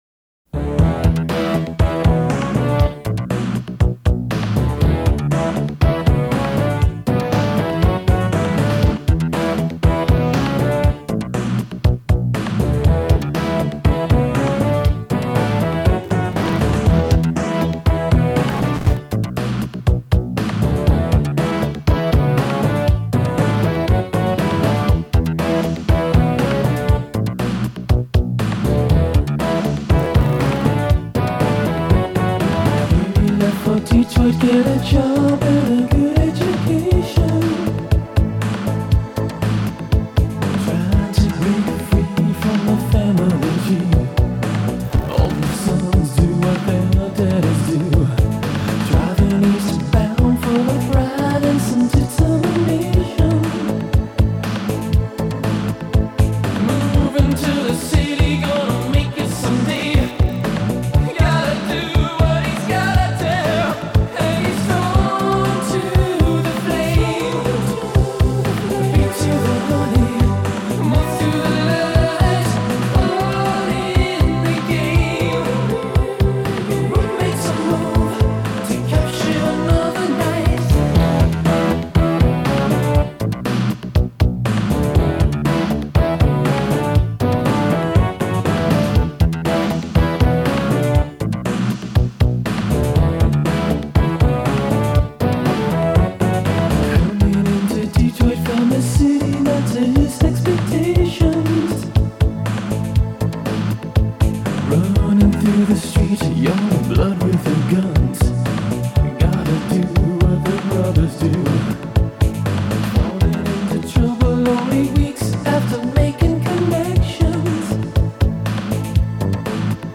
I recorded and produced this at CIM in 1987.